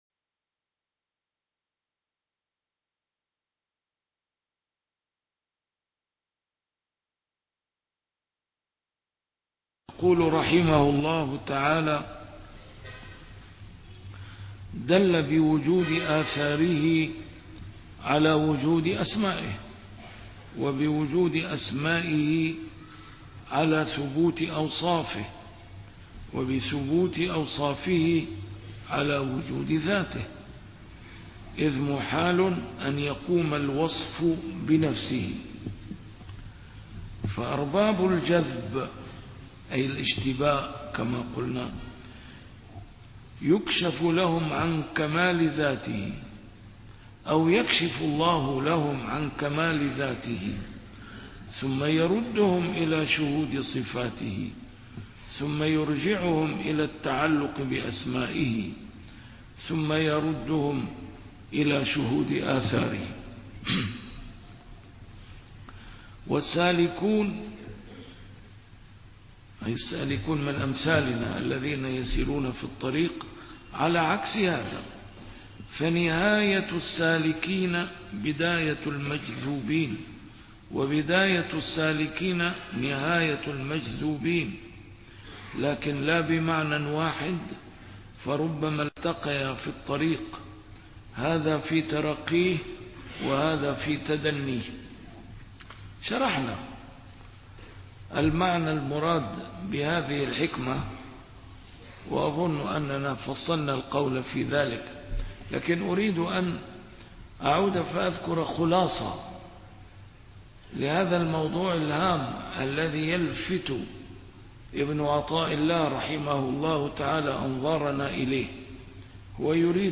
A MARTYR SCHOLAR: IMAM MUHAMMAD SAEED RAMADAN AL-BOUTI - الدروس العلمية - شرح الحكم العطائية - الدرس رقم 274 شرح الحكمة رقم 249